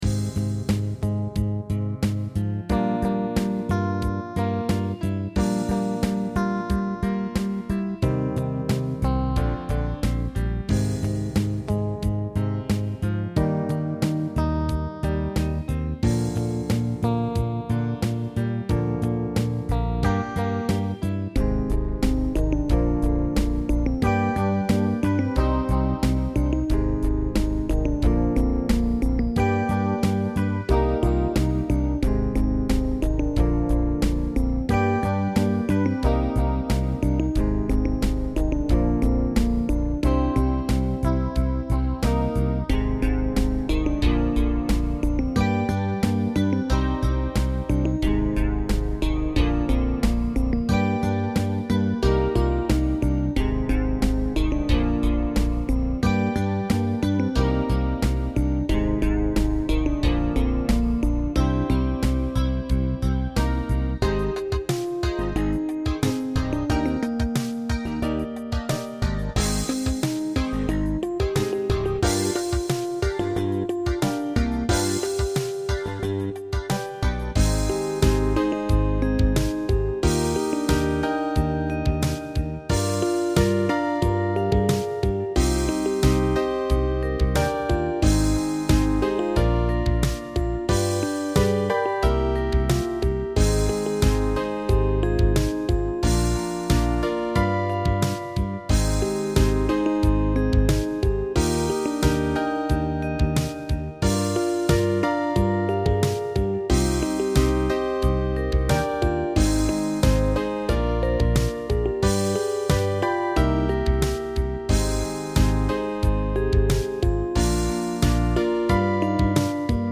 歌メロしか作れないとはいえ、ところどころ和音らしきものが頭の中では設定されていて、それらをできる限り入力していってやれば、曲にコードがついて、曲のテンポやジャンルを指定するとコードにもとずいてほぼ自動でアレンジしてくれるすぐれものです。
イントロ部、2小節目と3小節目のコードが同じなのが、自動アレンジされてしまって気持ち悪くなってます。
あ、でも、そういえば、イントロは自動伴奏のままいじらなかったな…
聴かせていただきましたけど、自動演奏がメインとは思えないくらいしっかりしてますね。
(歌は入れないんですか？)
キーはCmだった気がします。